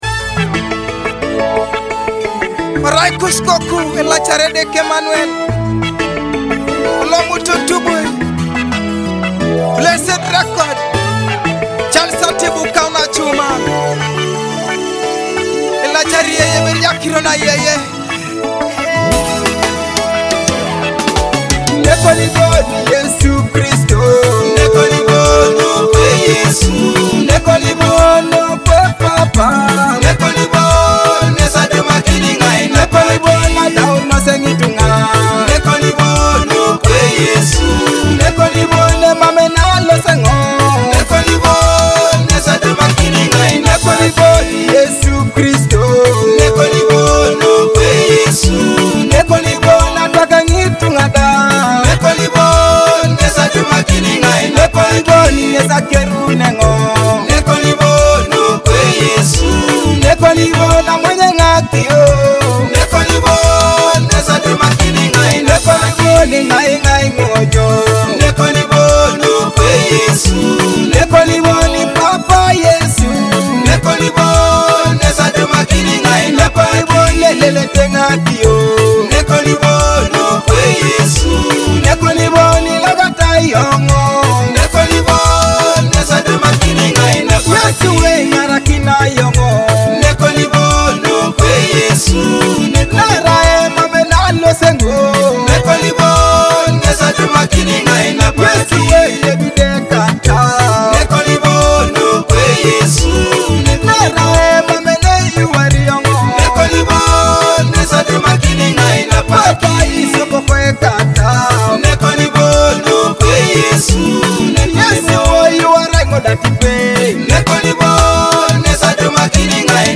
soul-stirring gospel hit